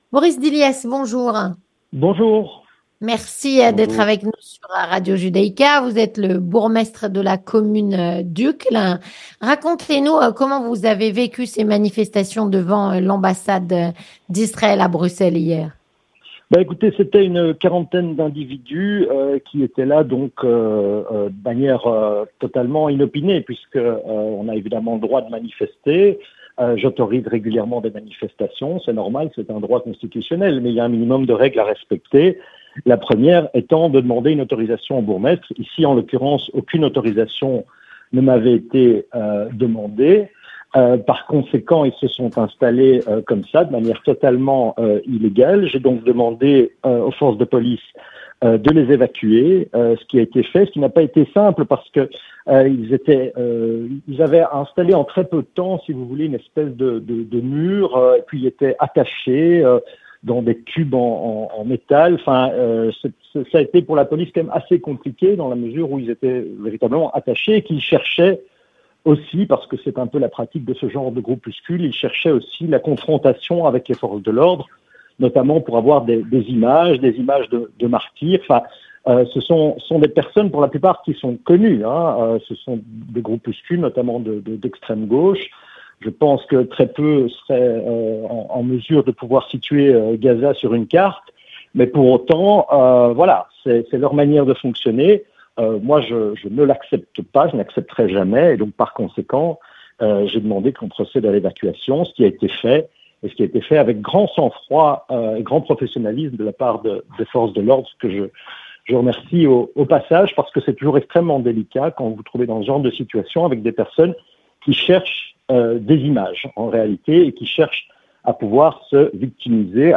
Avec Boris Dilliès, Bourgmestre (MR) de la commune d'Uccle.